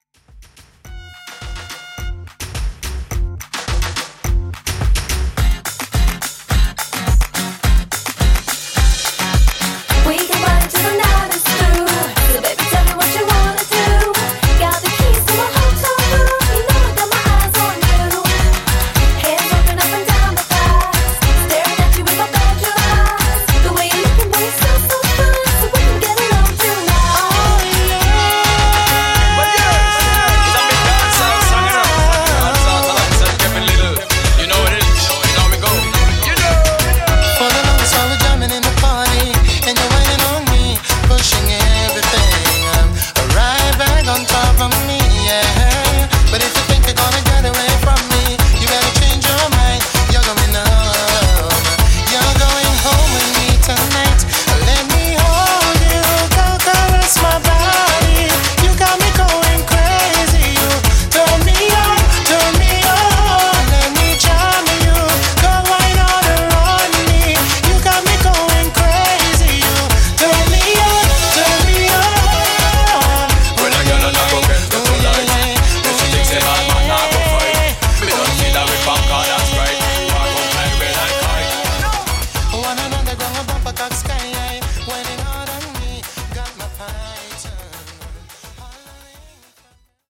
Party Mashup Moombah